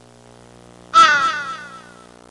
Crow In A Tunnel Sound Effect
Download a high-quality crow in a tunnel sound effect.
crow-in-a-tunnel.mp3